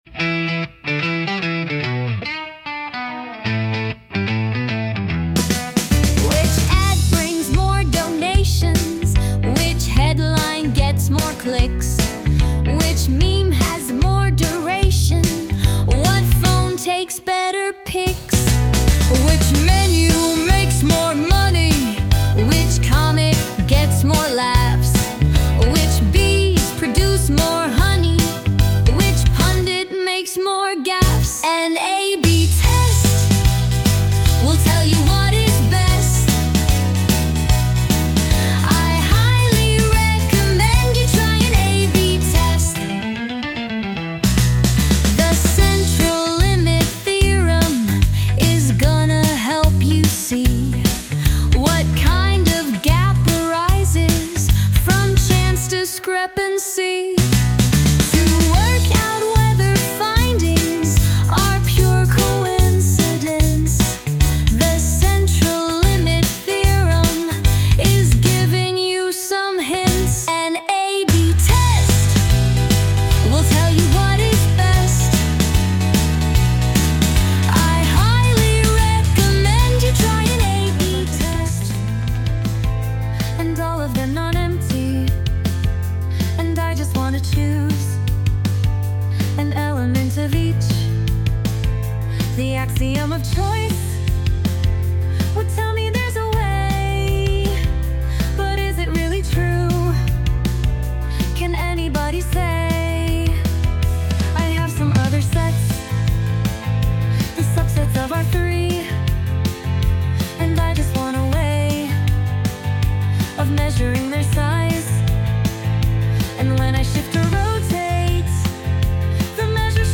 Warmup: lecture warm-up song zip or single file aided by local AI